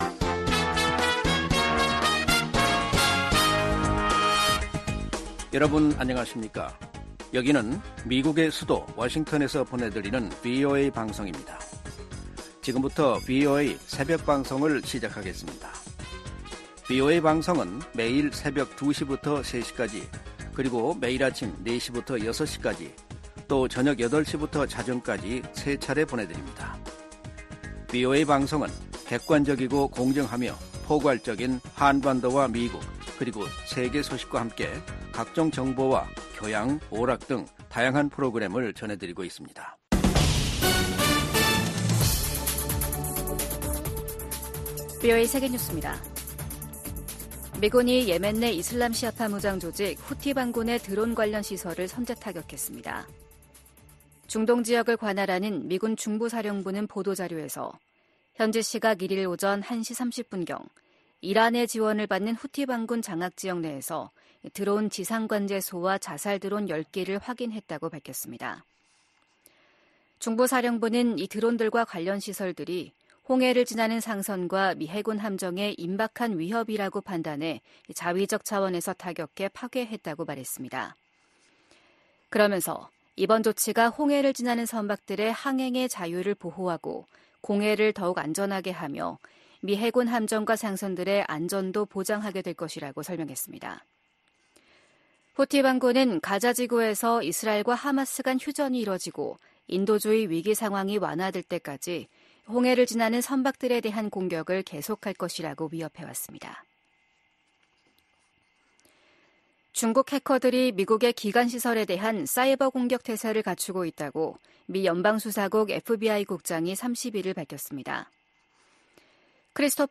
VOA 한국어 '출발 뉴스 쇼', 2024년 2월 2일 방송입니다. 한국 총선을 겨냥한 북한의 도발 가능성에 한반도 긴장이 고조되고 있습니다. 미 국무부는 최근 중국 외교부 대표단의 방북 직후 북한이 순항미사일을 발사한 점을 지적하며, 도발을 막는데 중국의 역할이 필요하다고 강조했습니다. 미한일 협력이 북한·중국 대응을 넘어 세계 현안을 다루는 협력체로 성장하고 있다고 백악관 국가안보보좌관이 말했습니다.